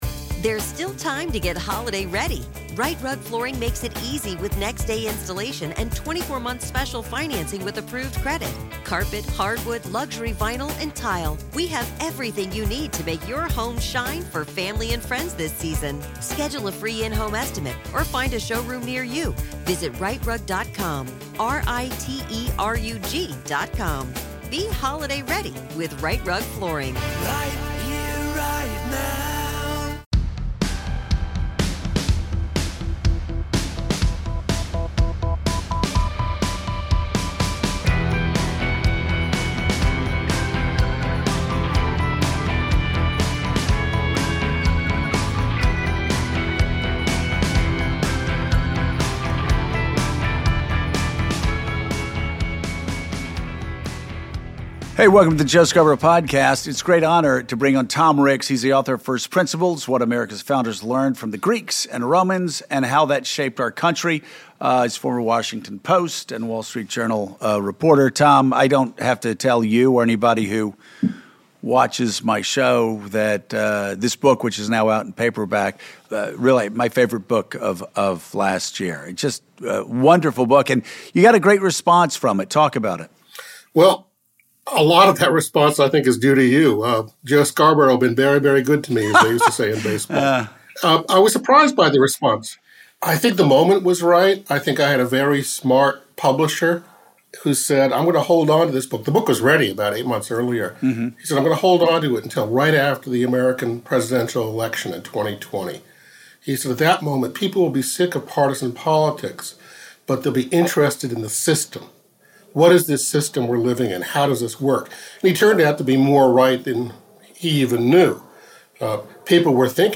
Facebook Twitter Headliner Embed Embed Code See more options Joe talks to Pulitzer Prize-winning author Thomas Ricks on the brilliance of George Washington, the hypocrisy of the slaveholder whose Declaration freed more humans than any figure in history, how James Madison saved America from the illiberalism of Donald Trump, why General Sherman was the greatest soldier in U.S. history, and what we should learn from 20 years of military misadventures.